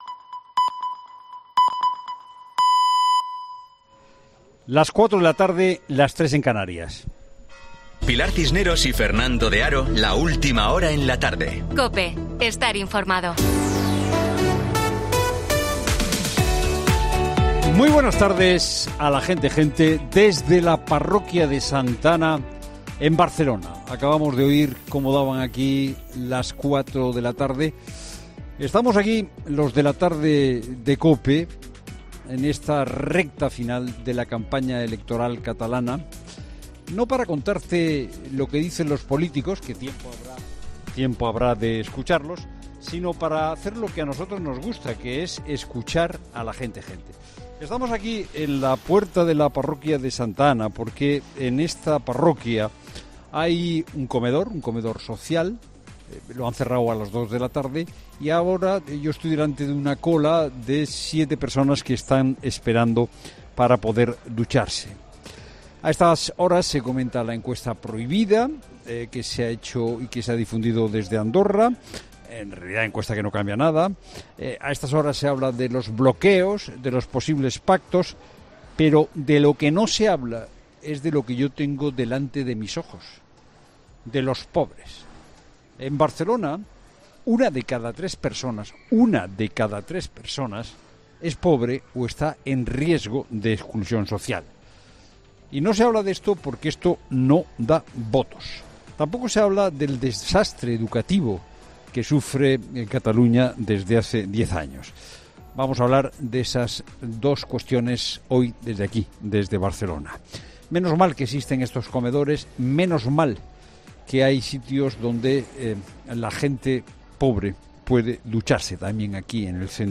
desde un comedor social de Barcelona
Monólogo